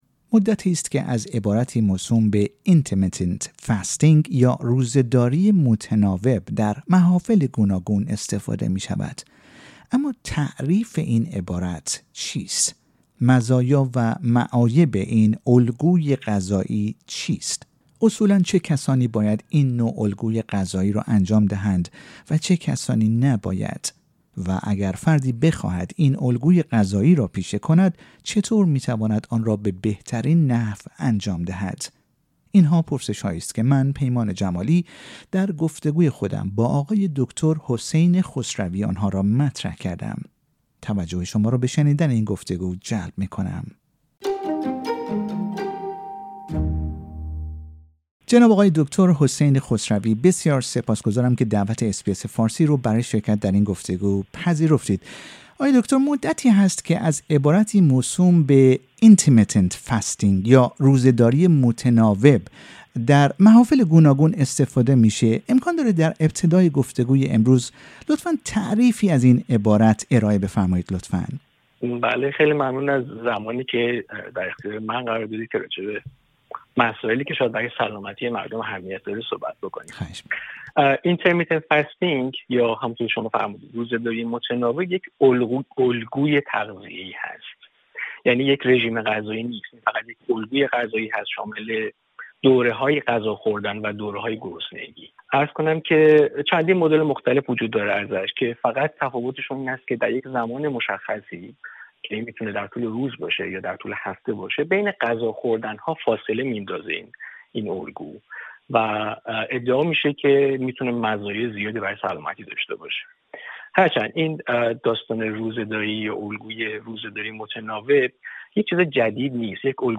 در این گفتگو، به مزایا و معایب این الگوی غذایی، افرادی که باید و نباید از آن استفاده کنند، و همچنین نحوه اجرای صحیح این روش غذایی به بهترین نحو، پرداخته شده است.